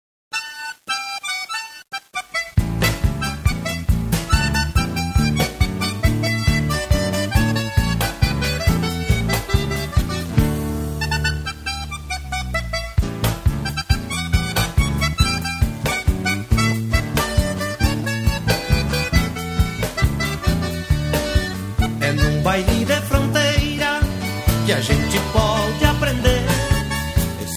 Chamamé
accordion, guitar, vocal